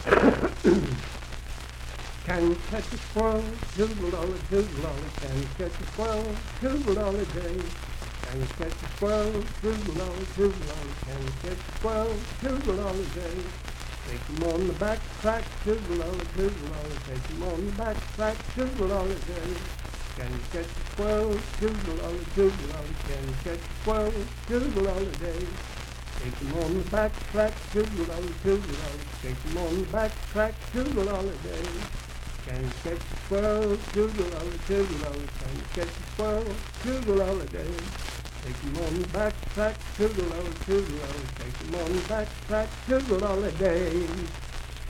Unaccompanied vocal music
in Mount Storm, W.V.
Verse-refrain 7(4w/R).
Dance, Game, and Party Songs
Voice (sung)